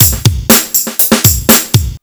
RUFFBEAT 121 1.wav